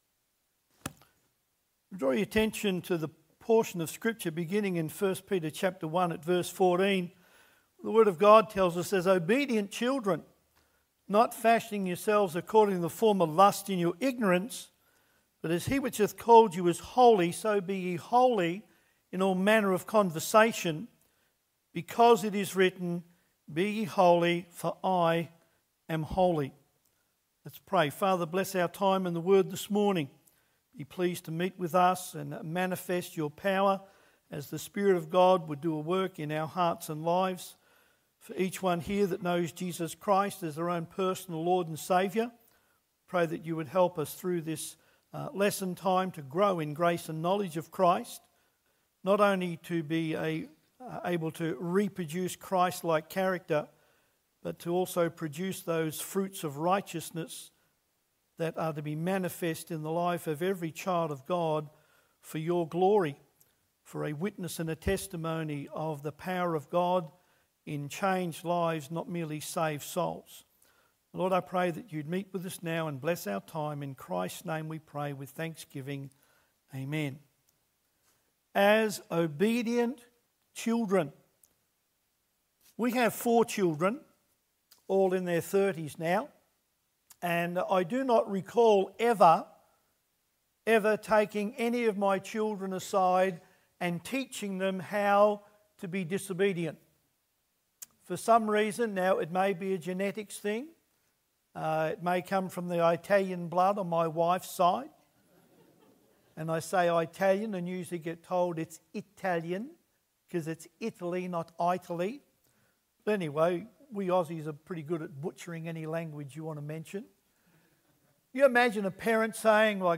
I Peter 1:14-16 Service Type: Adult Sunday School Class « Halloween is No Treat Why Do People Still Die in Their Sin?